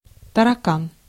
Ääntäminen
IPA: [ka.faʁ]